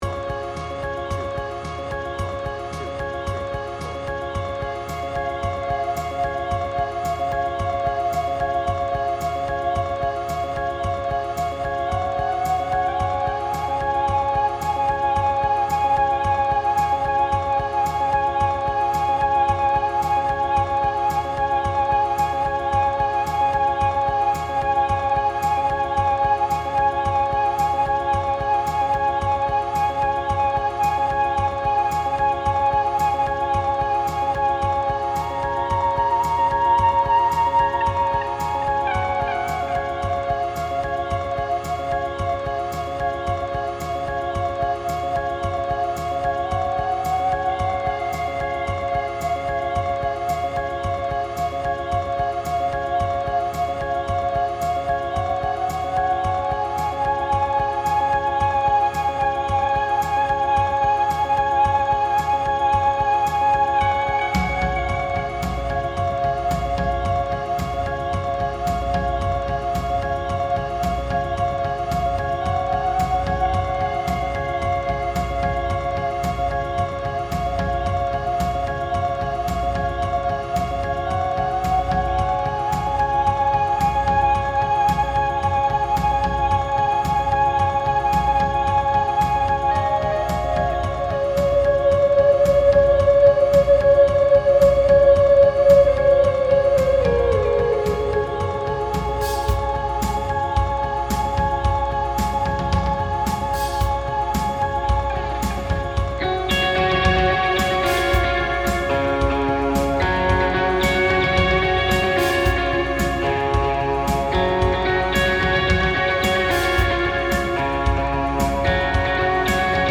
BPM : 111
Tuning : Eb
Without vocals